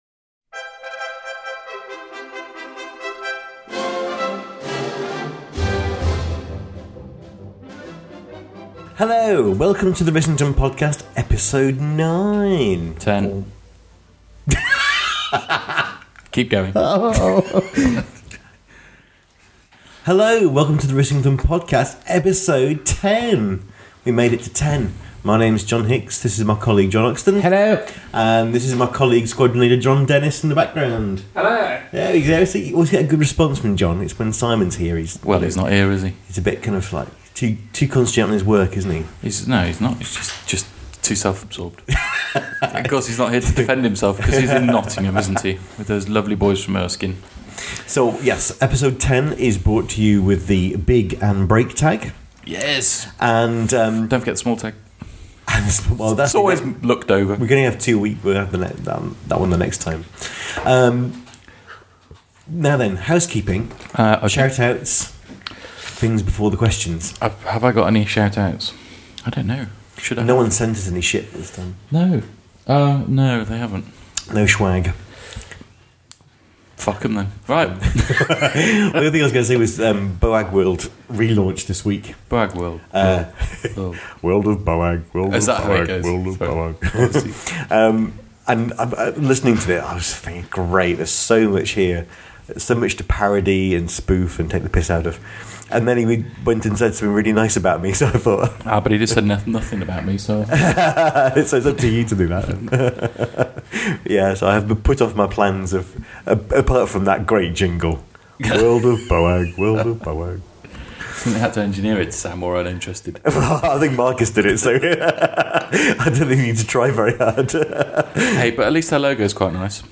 The outtakes are in episode. In an attempt to streamline the process and get more episodes out more often we are experimenting with no editing.